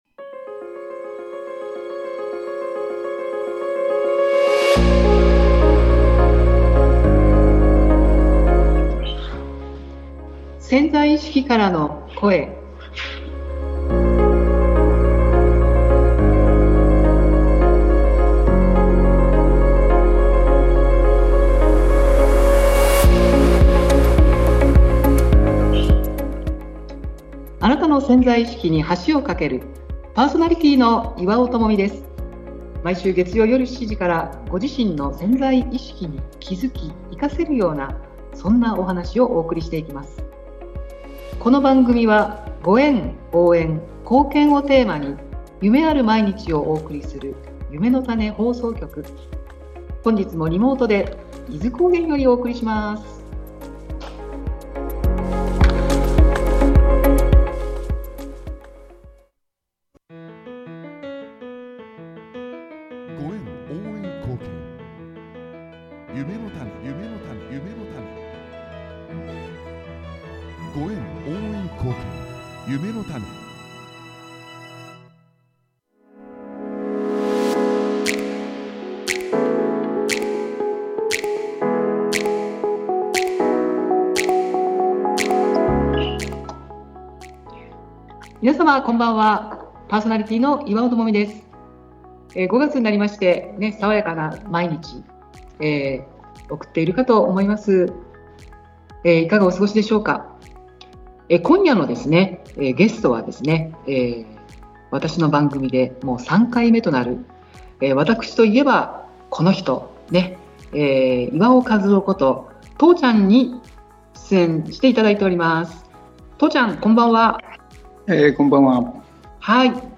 音声ファイル： 量子場調整®を創始した歩み この音源はゆめのたね放送局の許可を得て公開しています。